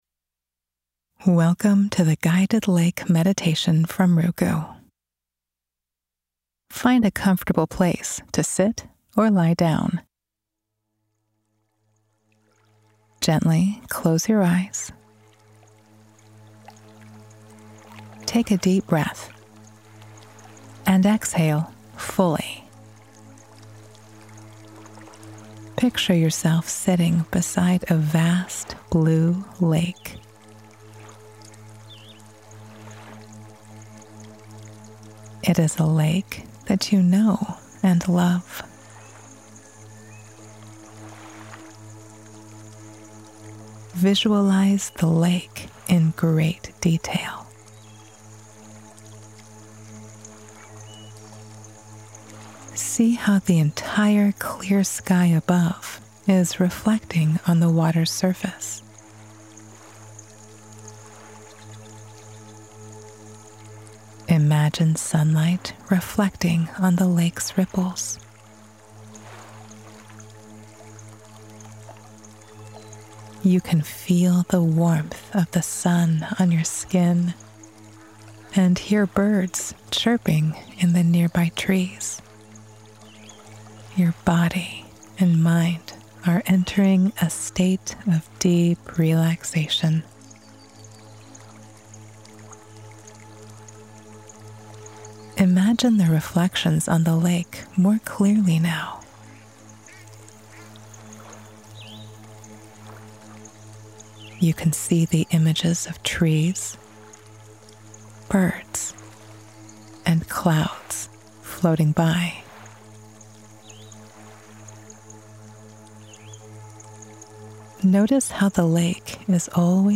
Guided Imagery Lake